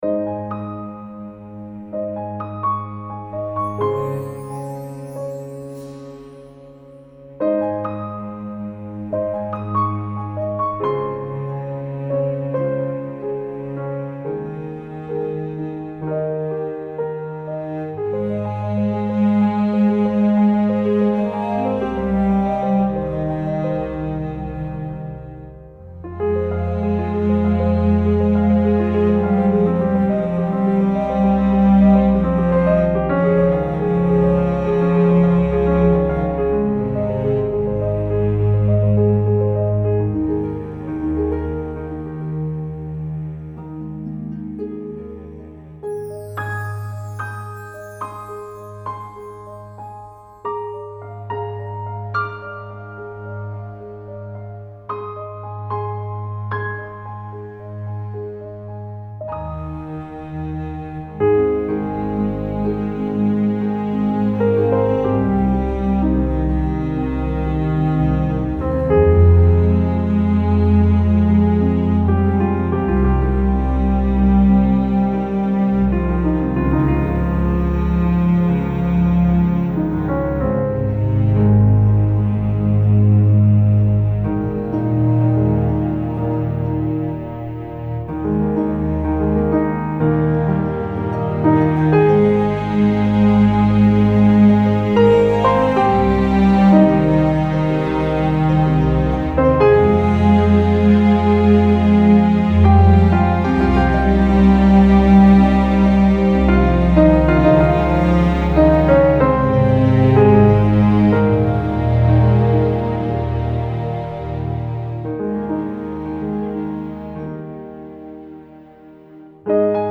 Audition score
• Newman/Elfman Imitation